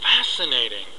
英語發音 English Pronunciation
(加連線者為連音，加網底者不需唸出聲或音很弱。)